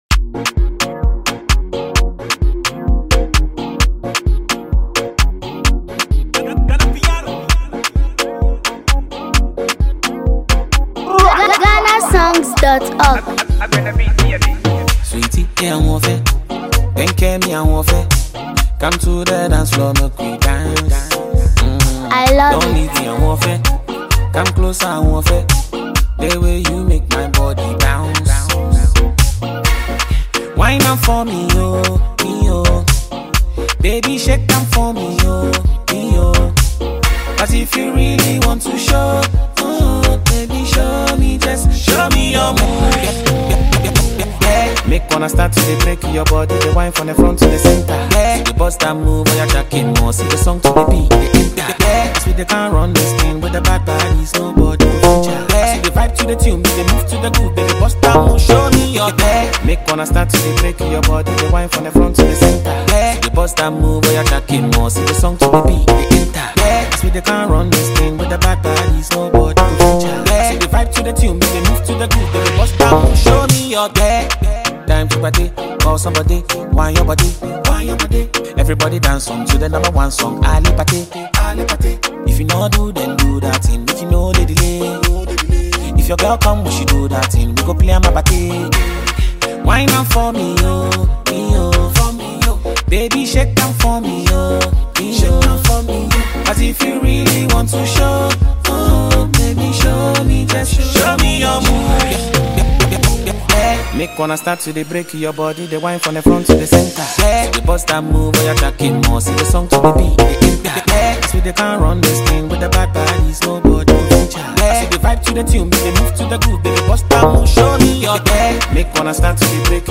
heavy kicks, sharp snare patterns, and rhythmic synths